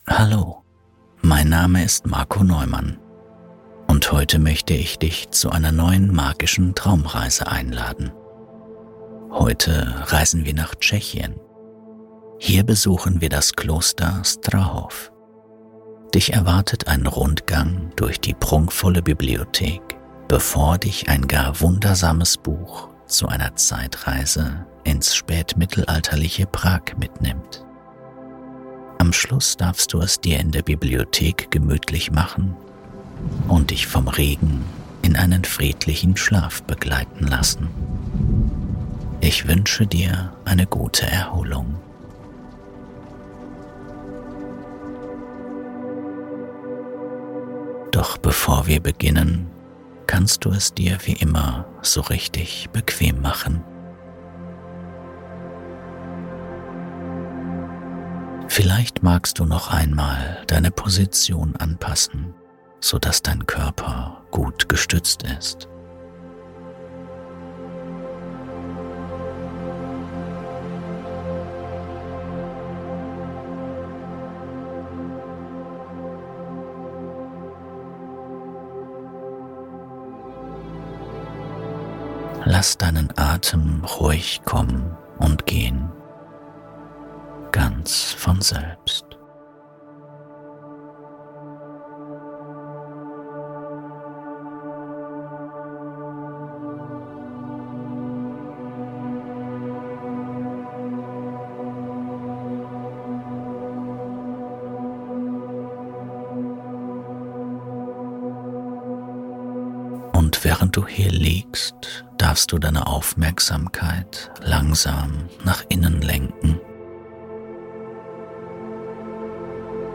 Prag 1348: Die Zeitreise, die selbst EXTREME Schlafprobleme löst! + Regen